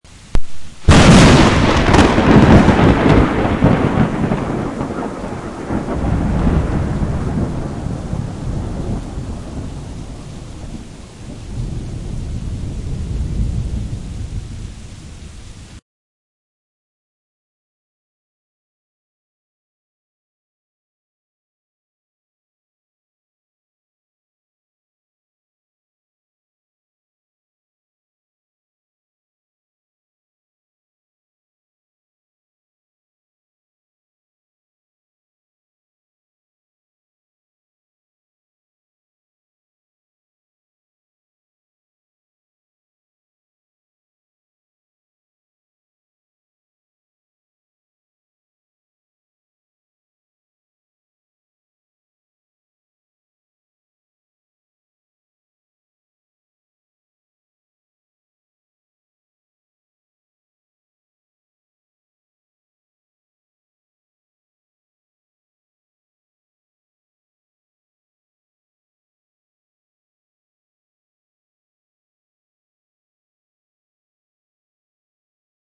Download Thunder sound effect for free.
Thunder